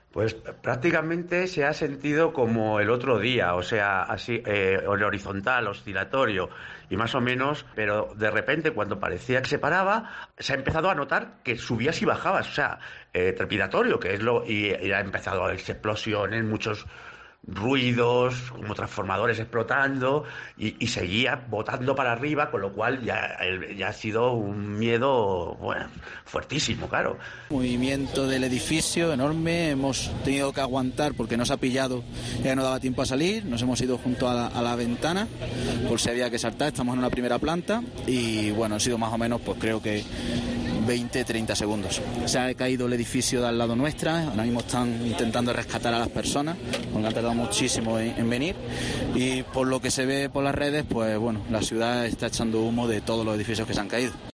españoles en México, cuentan en 'La Linterna' cómo han sentido el terremoto